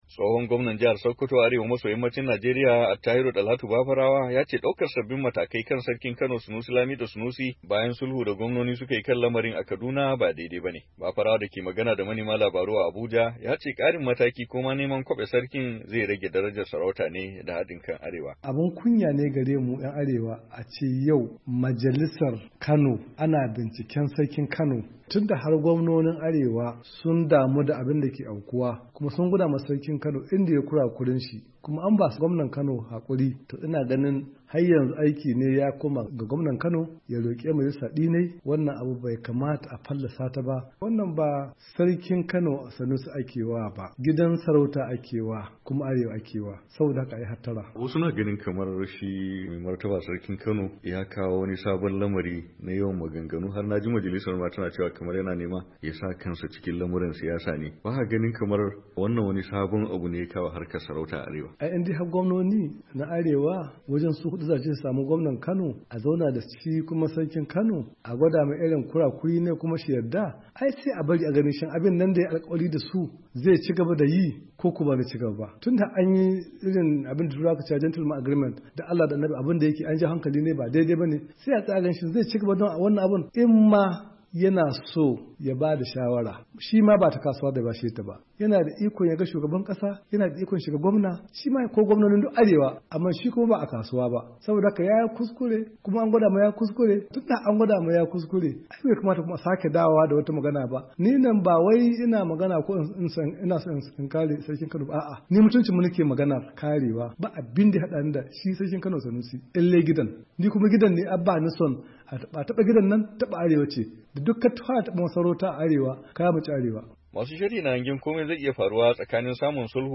Bafarawa da ke magana da manema labarai a Abuja, ya ce karin mataki ko neman kwabe sarkin zai rage darajar sarauta ne da hadin kan arewa.